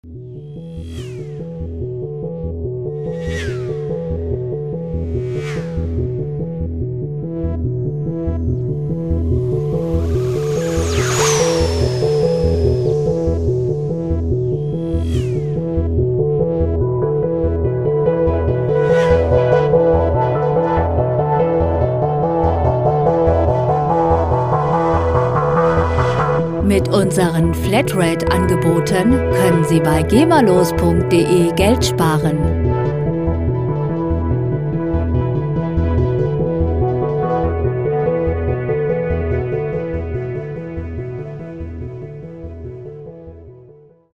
Elektronische Musik - Moderne Welt
Musikstil: Elektronische Musik
Tempo: 72 bpm
Tonart: E-Moll
Charakter: spannungsvoll, ostentativ
Instrumentierung: Analog Synthesizer, Effekte